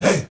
SouthSide Chant (31).wav